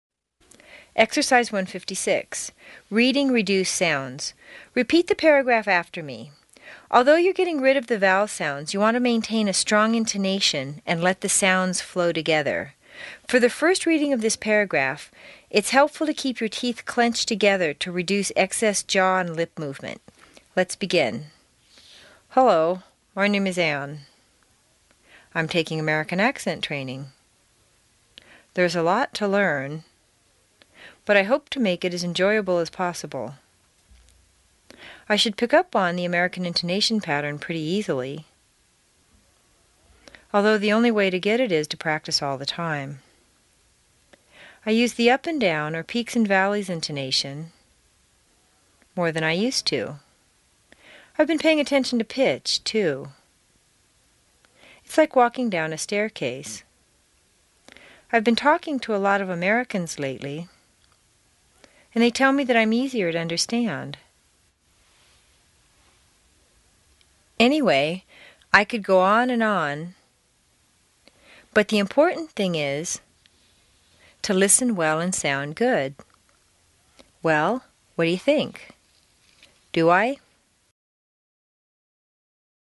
美语口音训练第二册29 听力文件下载—在线英语听力室